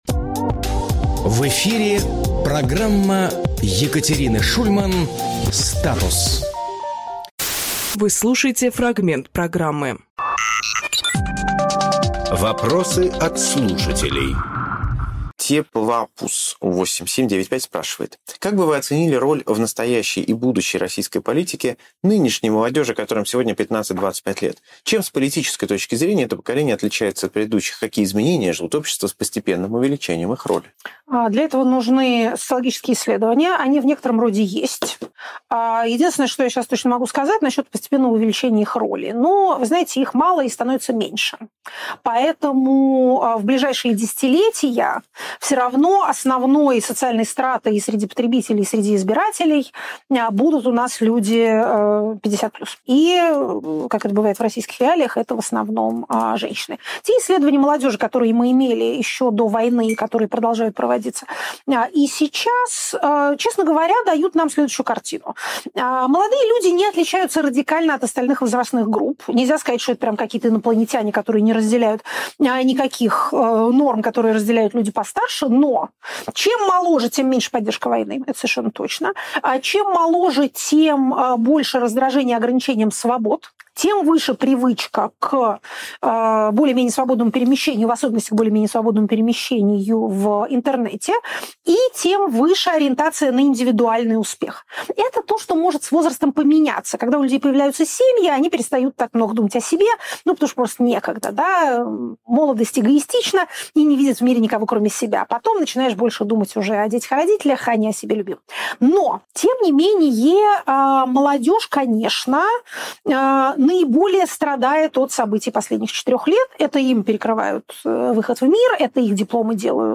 Фрагмент эфира от 24.03.26